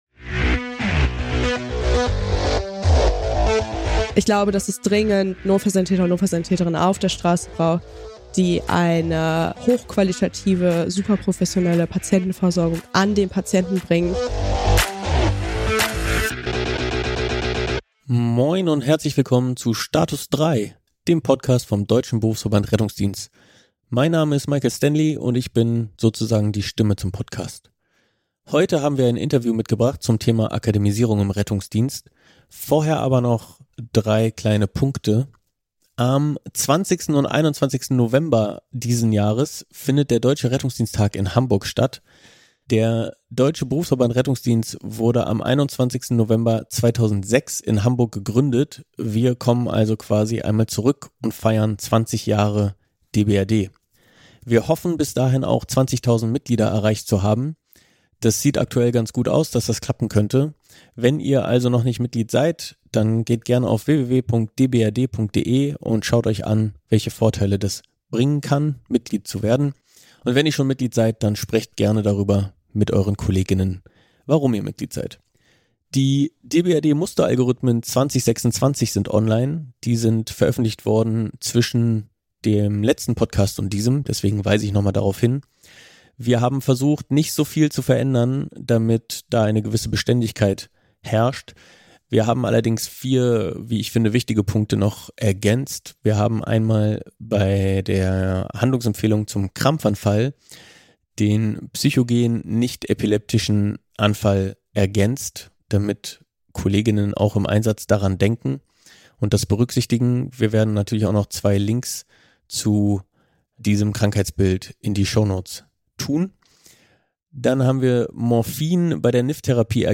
Es wird viel ÜBER Akademisierung gesprochen, wir haben MIT einer Notfallsanitäterin gesprochen, die aktuell berufsbegleitend studiert. Warum studiert sie? Was studiert sie, und was erhofft sie sich?